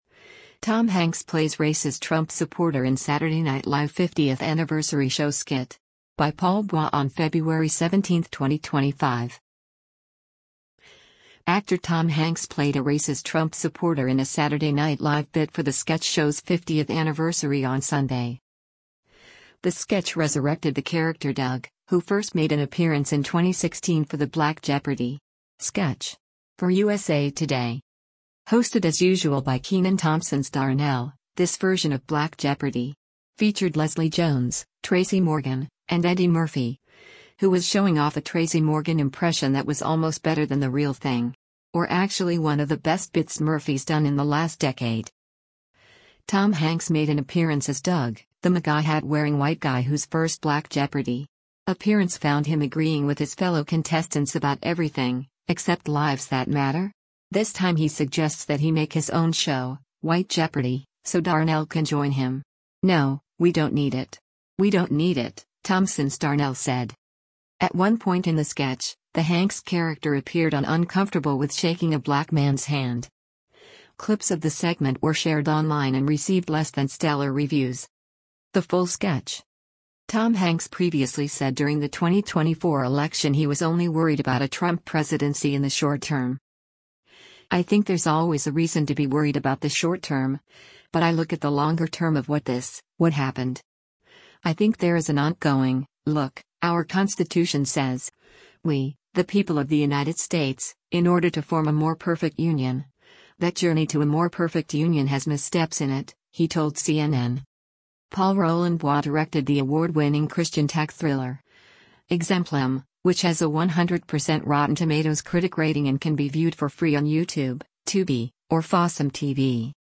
Actor Tom Hanks played a racist Trump supporter in a Saturday Night Live bit for the sketch show’s 50th anniversary on Sunday.
Hosted as usual by Kenan Thompson’s Darnell, this version of “Black Jeopardy!” featured Leslie Jones, Tracy Morgan and Eddie Murphy, who was showing off a Tracy Morgan impression that was almost better than the real thing.
The full sketch: